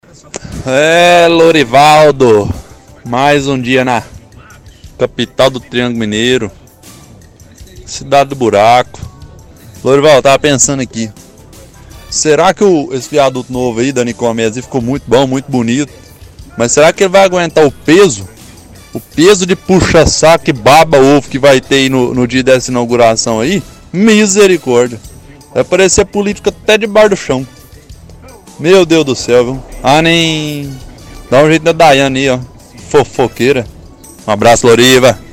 -Ouvinte diz que Uberlândia é a cidade do buraco, diz que viaduto ficou muito bonito e debocha dizendo se viaduto vai aguentar o peso dos “puxa saco e baba ovo” que estarão no dia da inauguração. Diz que vai aparecer político até debaixo do chão.